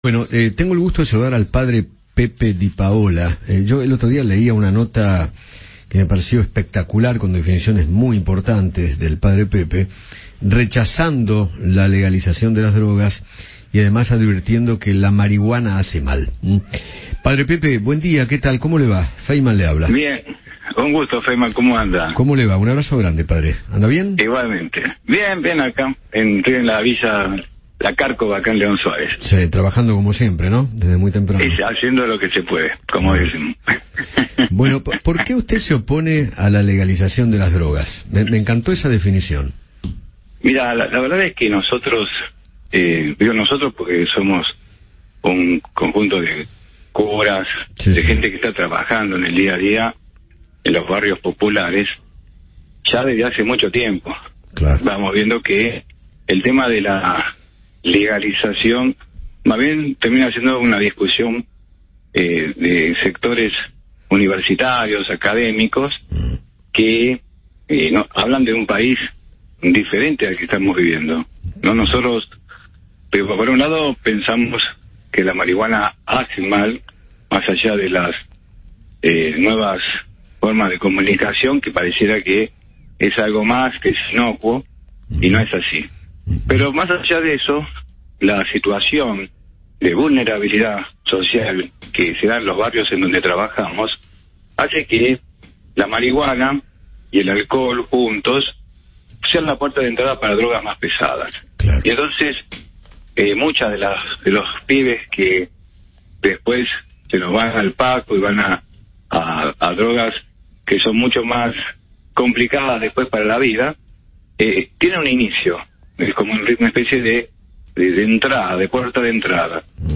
Eduardo Feinmann charló con el Padre Pepe Di Paola sobre la legalización de las drogas e hizo hincapié en la necesidad de “protocolos de atención para los adictos”.